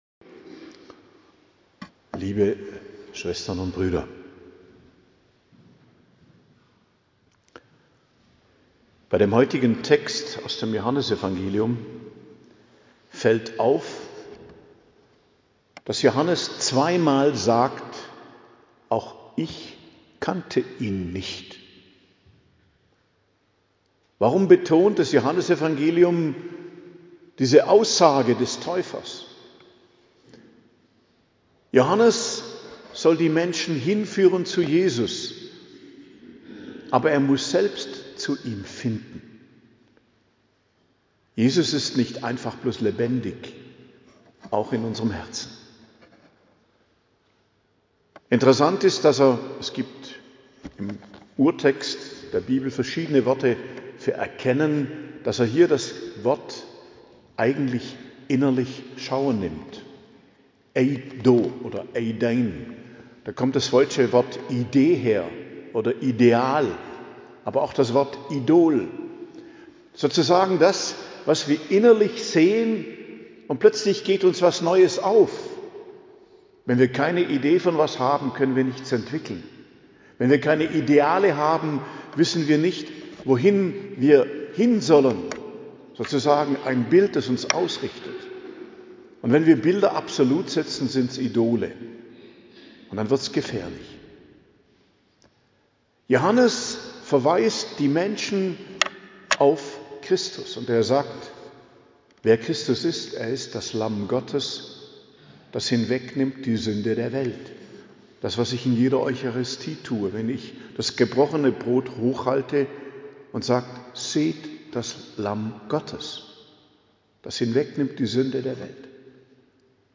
Predigt zum 2. Sonntag i.J., 18.01.2026